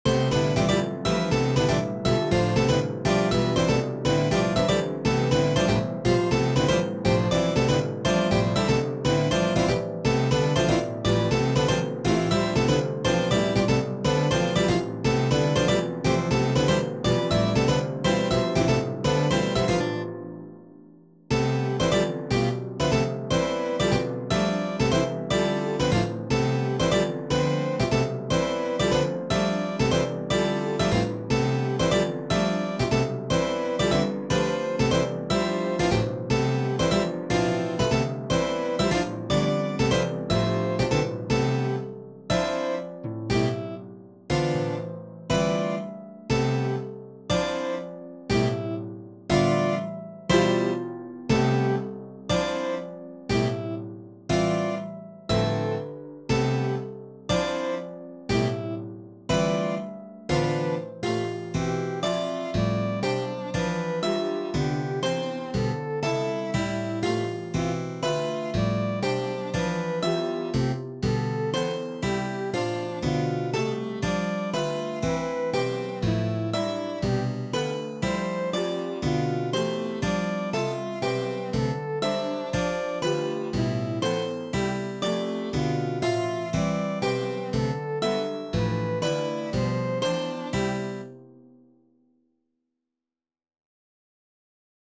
The Modern Symphony Music Prose Original Compostion.